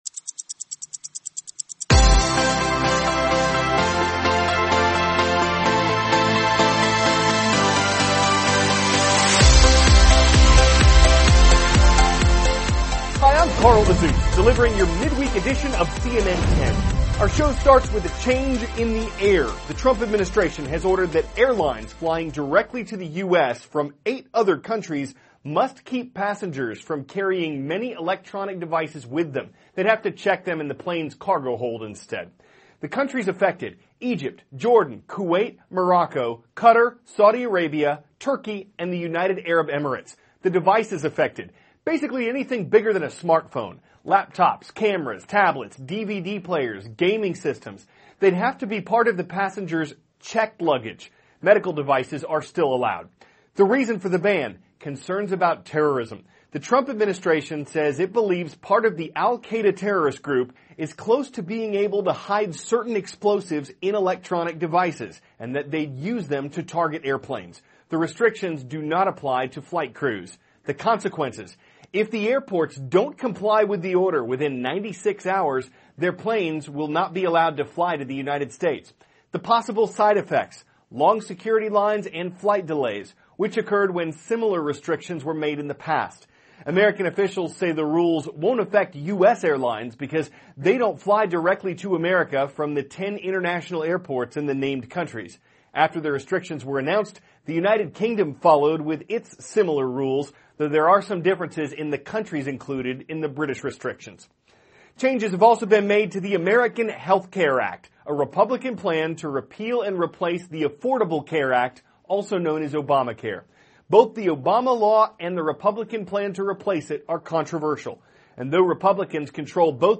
*** CARL AZUZ, cnn 10 ANCHOR: Hi.